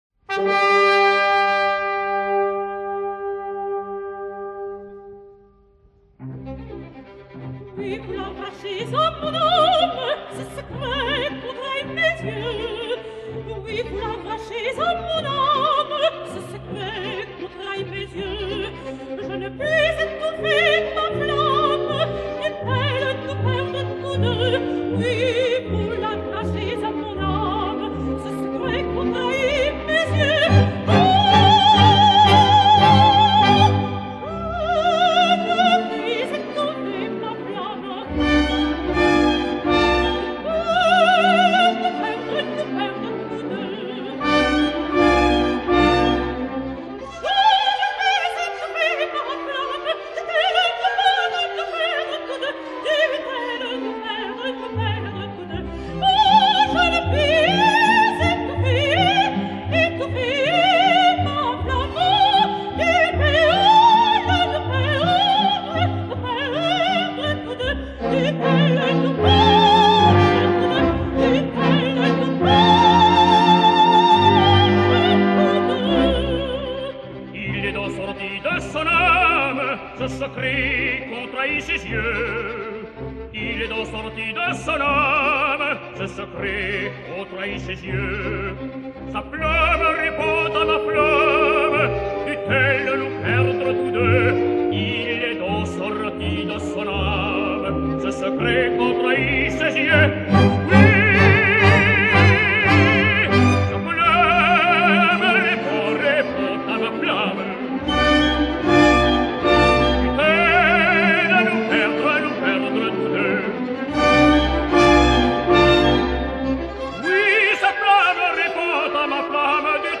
Tony Poncet sings Guillaume Tell: